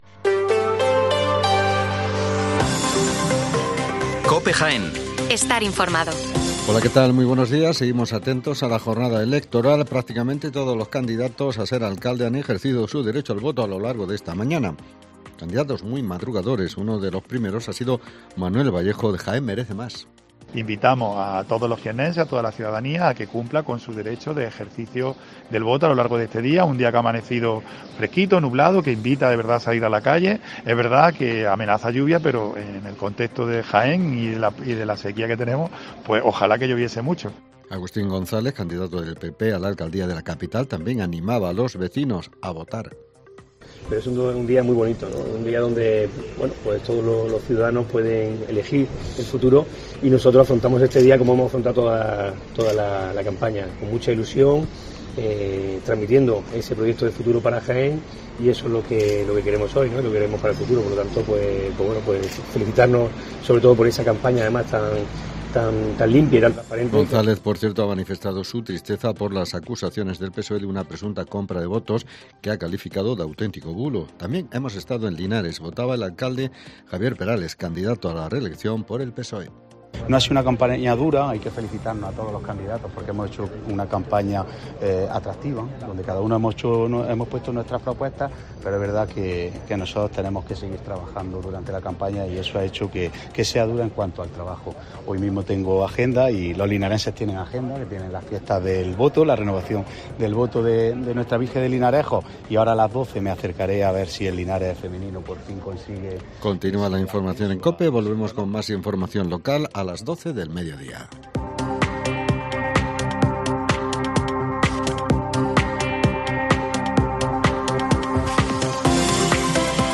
Especial Elecciones Municipales en Jaén. El informativo de las 12:05 horas